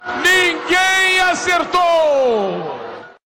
Faustão dizendo "ninguém acertou" no Domingão do Faustão.